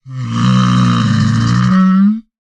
assets / minecraft / sounds / mob / camel / stand4.ogg